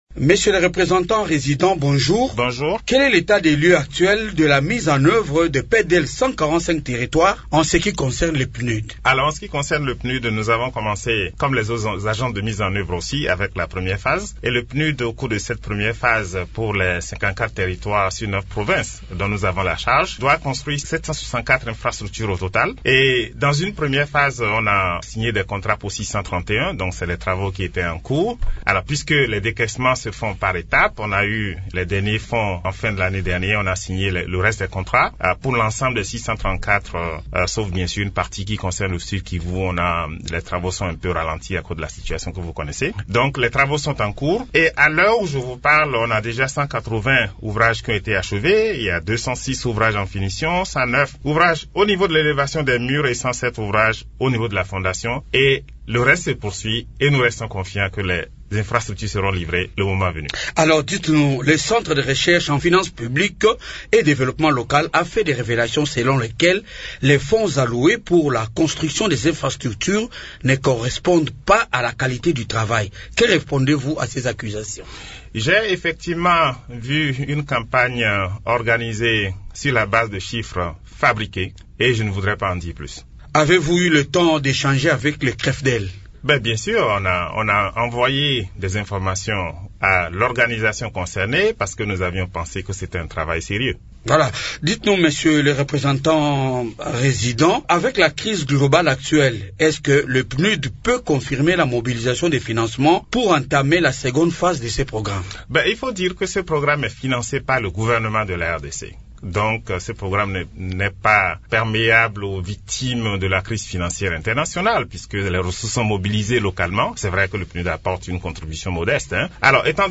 Au cours d'une interview a Radio Okapi, il a rassuré l’opinion publique quant à la livraison dans les délais de toutes les infrastructures dont le PNUD supervise la construction dans le cadre de ce vaste programme.